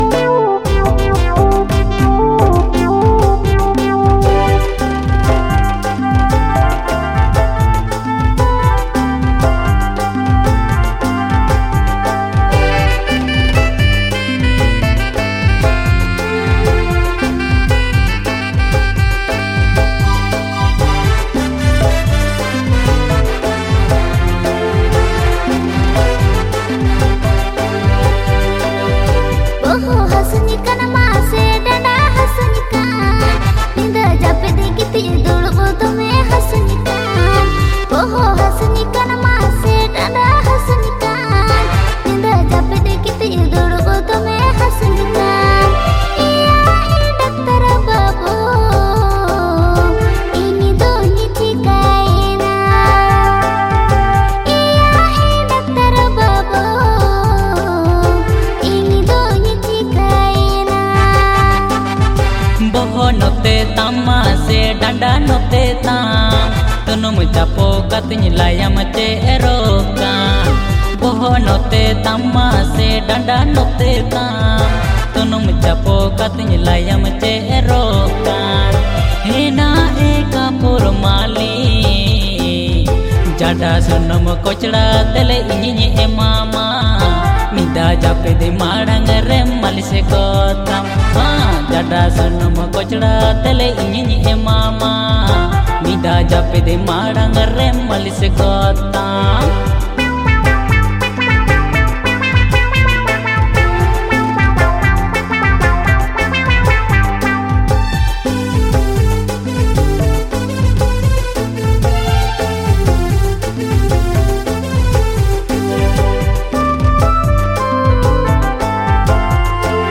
• Male Artist
• Female Artist